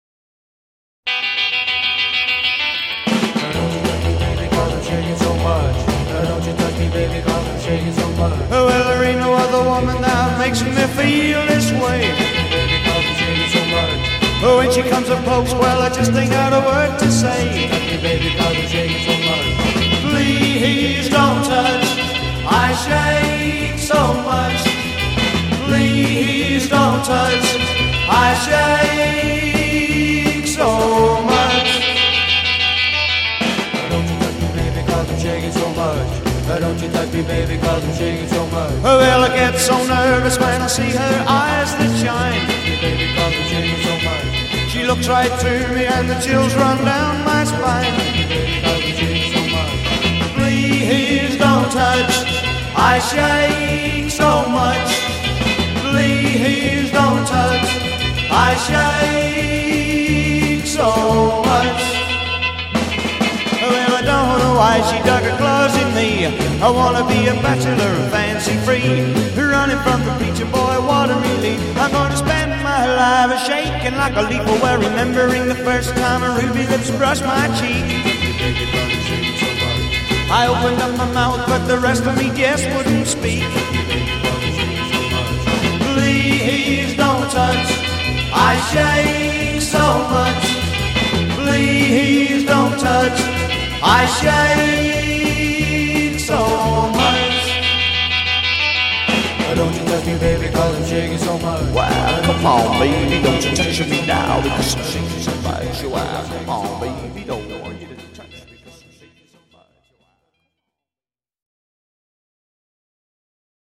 drums and bass accompany with guitar fill.   b
A2 refrain : 8 double-tracked or unison vocal. c
A' intro3 : 2 Solo guitar variation on introduction.
B chorus : 8 vocal solo with drums and bass (drop guitar) e